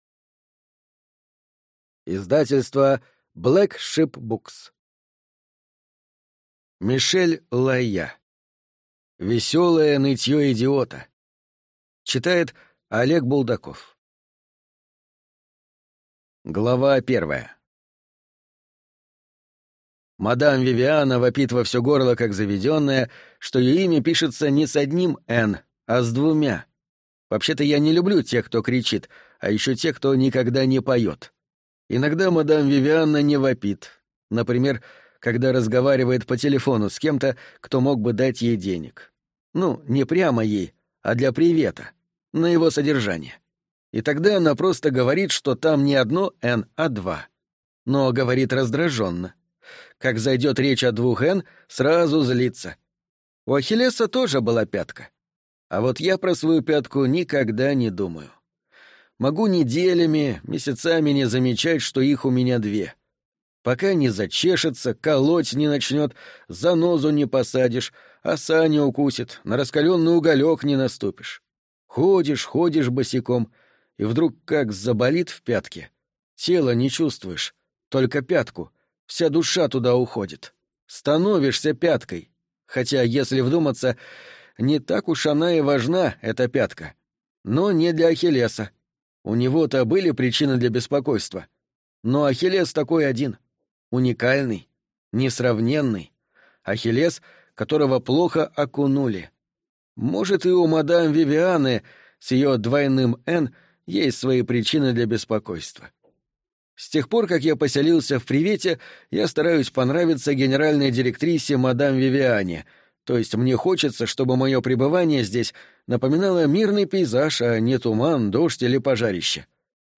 Аудиокнига Весёлое нытьё идиота | Библиотека аудиокниг